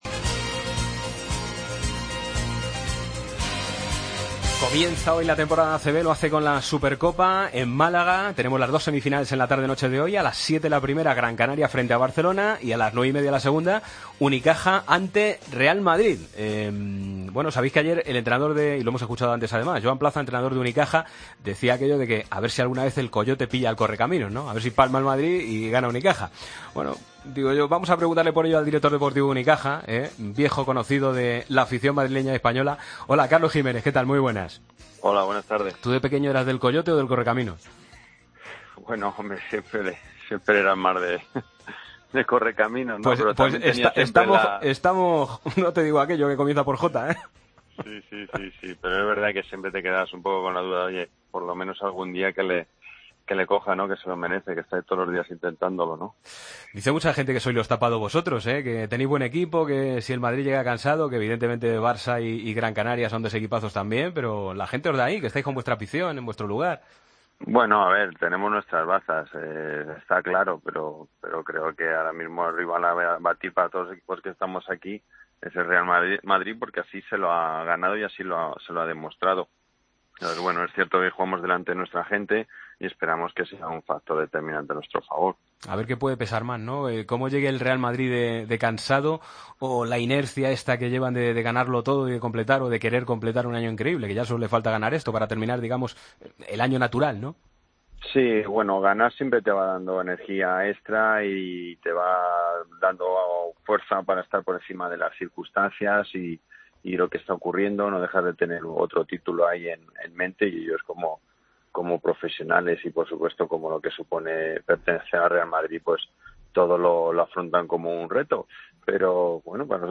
El exjugador del baloncesto y ahora miembro del cuerpo técnico del Unicaja de Málaga, analiza en los micrófonos de Deportes COPE la Supercopa Endesa que se disputa este fin de semana.